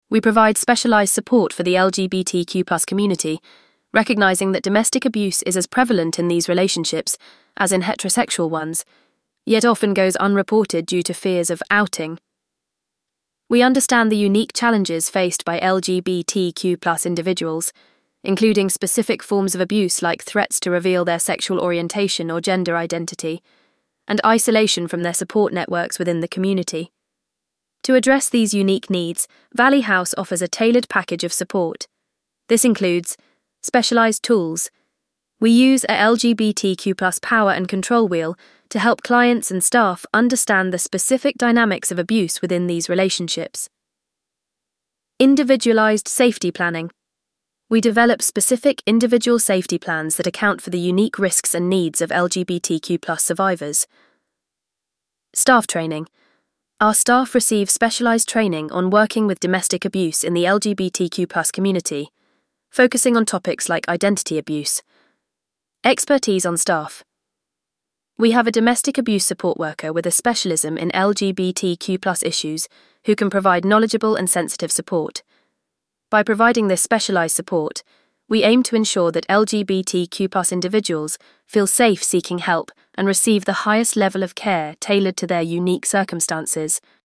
VOICEOVER-LGBTQ.mp3